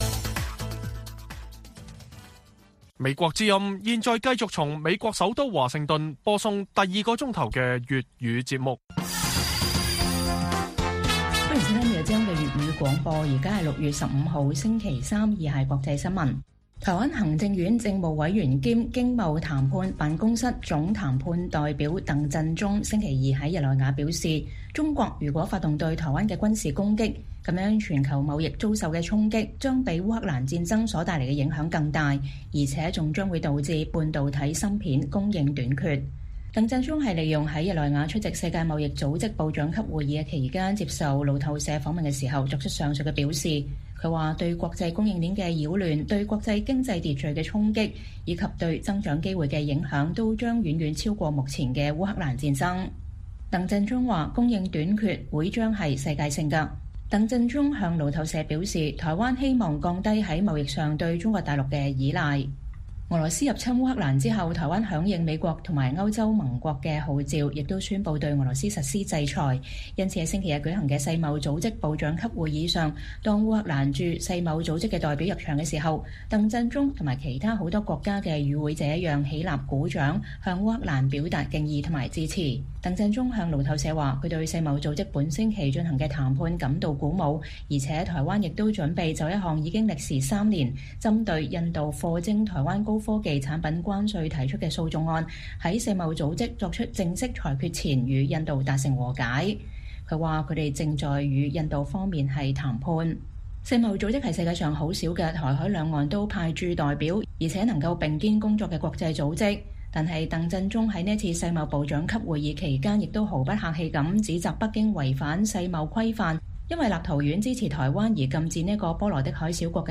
粵語新聞 晚上10-11點: 台灣再發警告指若北京攻擊台灣，全球貿易往來將遭受比烏克蘭戰爭更大衝擊